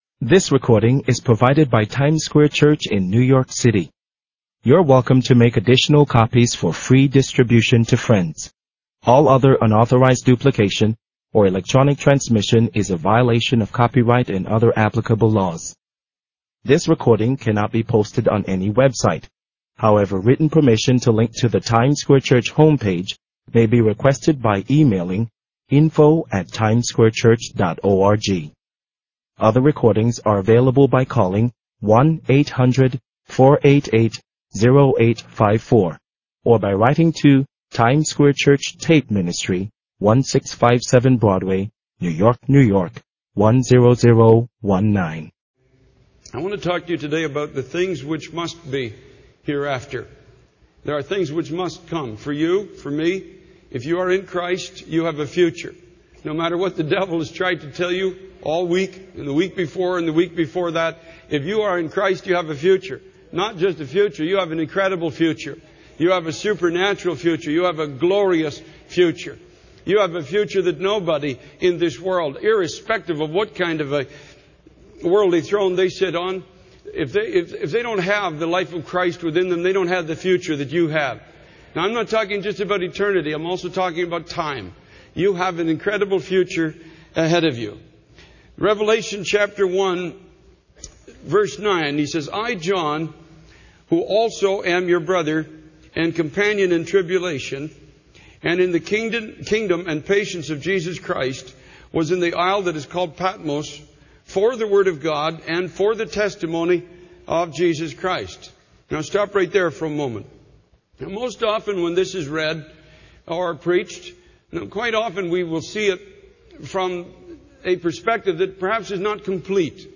In this sermon, the speaker emphasizes the importance of being a living testimony for God in our generation. He encourages believers to go where there is difficulty and opposition, boldly proclaiming the testimony of Jesus Christ.